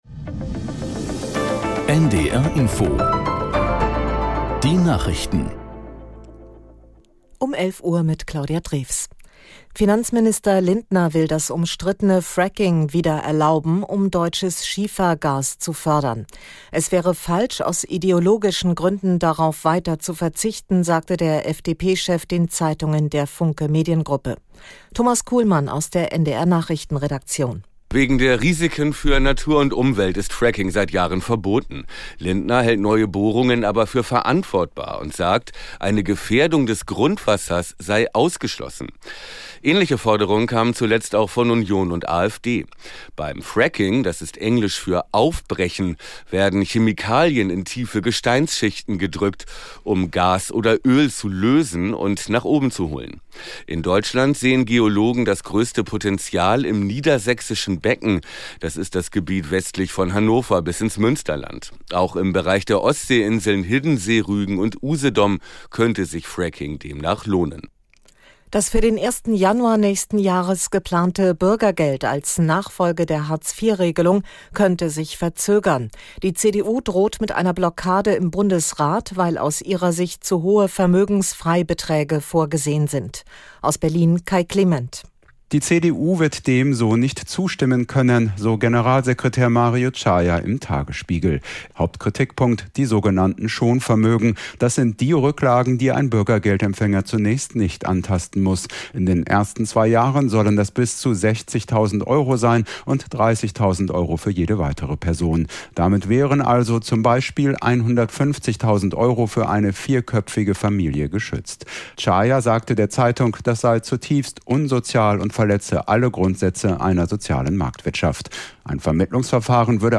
Nachrichten - 30.10.2022